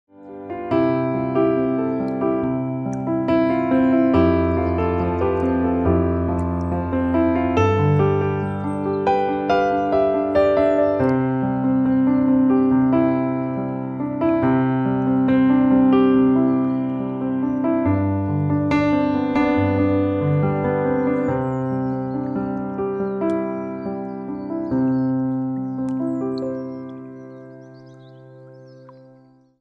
Hip-hop piano beat live performance